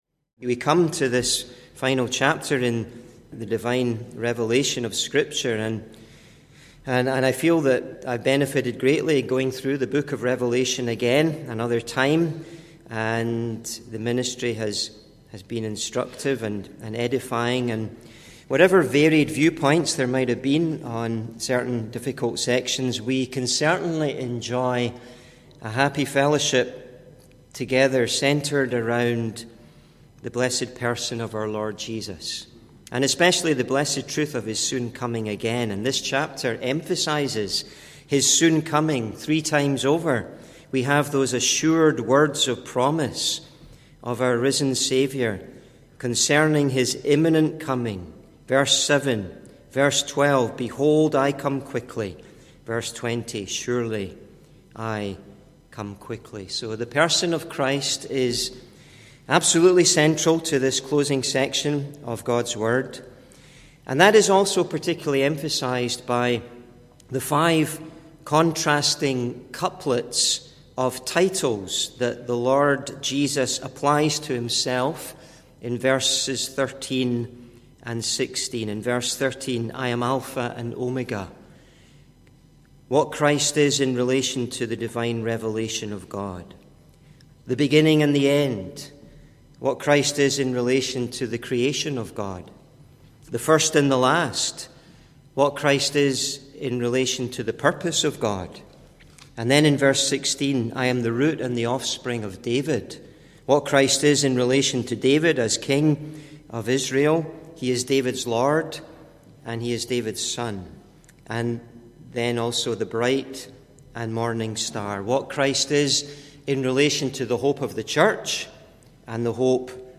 (Recorded in Parkview Street Gospel Hall, Winnipeg, MB, Canada, 16th Nov 2025)
Individual Messages on Eschatology